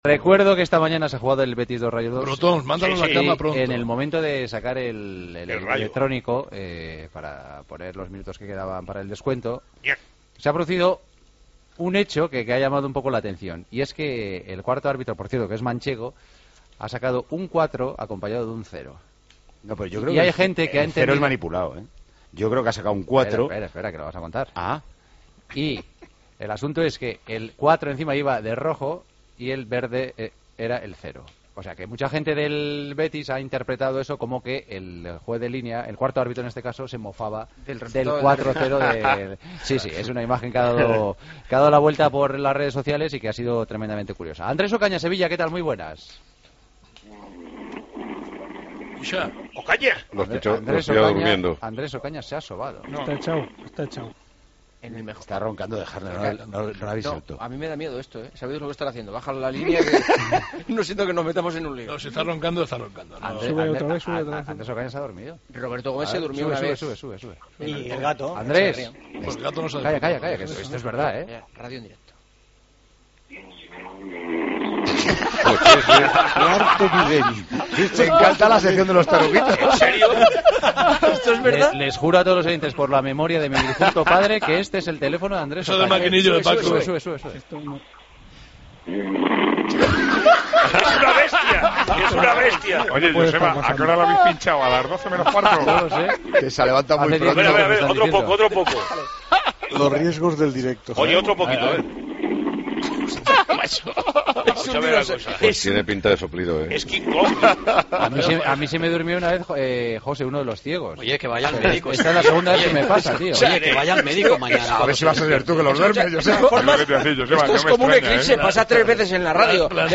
Escucha los ronquidos